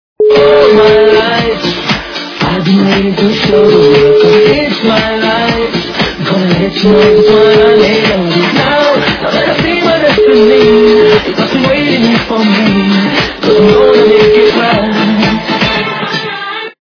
западная эстрада
качество понижено и присутствуют гудки.